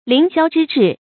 凌霄之志 líng xiāo zhī zhì
凌霄之志发音